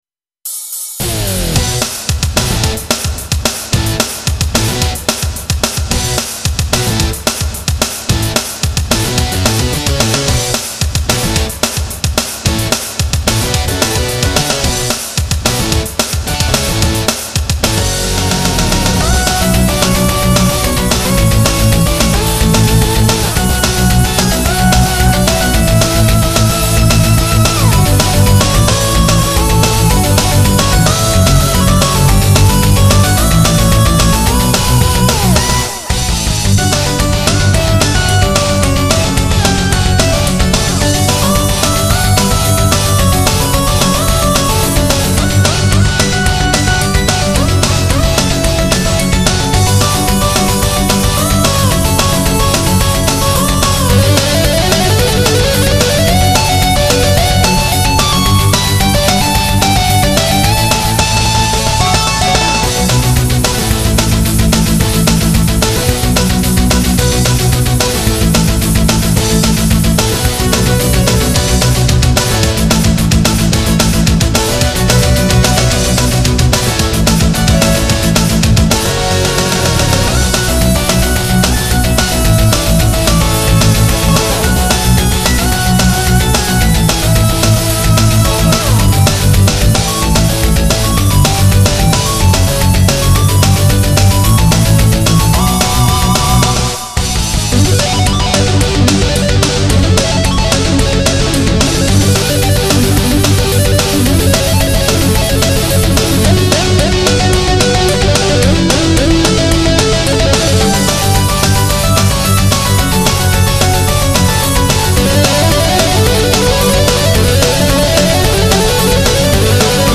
YAMAHA MU2000を使ってゲームBGMのメタルアレンジをしています